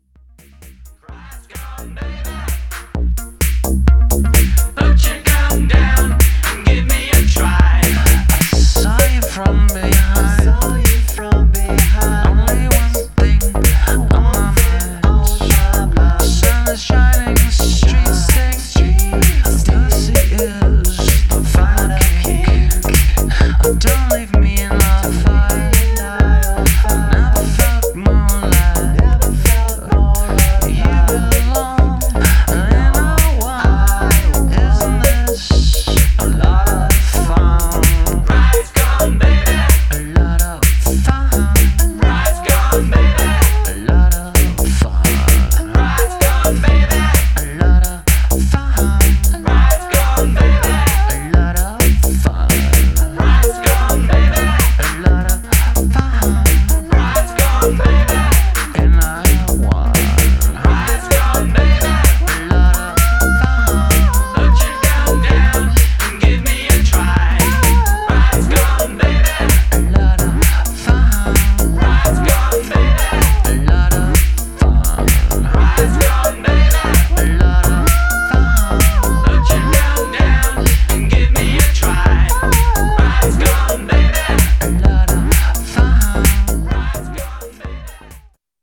Styl: Electro, House, Breaks/Breakbeat